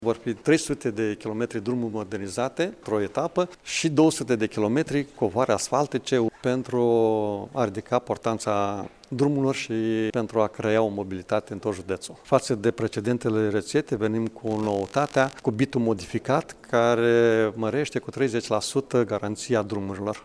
Preşedintele instituţiei, Maricel Popa, a precizat că judeţul Iaşi are doar 500 de kilometri de drumuri modernizate,  dintr-un total de aproape 1100.